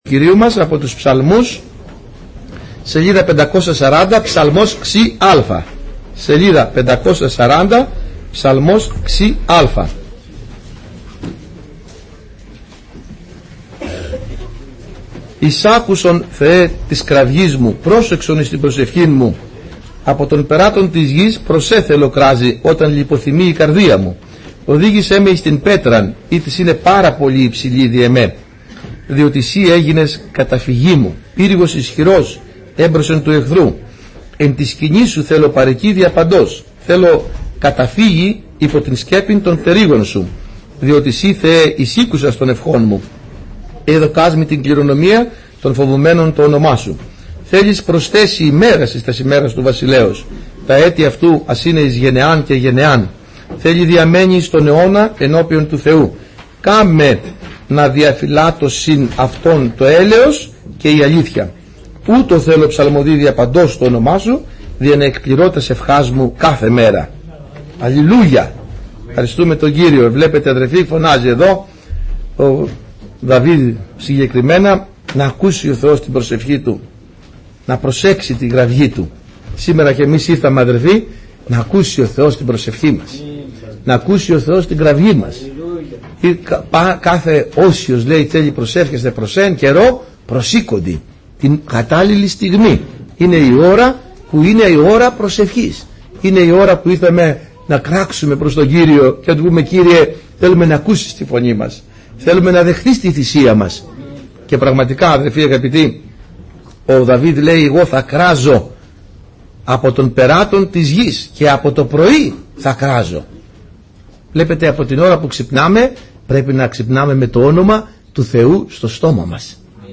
Κυριακάτικα Ημερομηνία